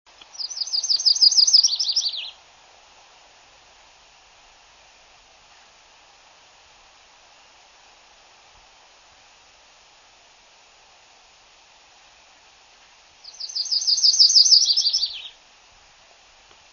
Yellow Rumped Warbler
warbler_yellow-rumped_832.wav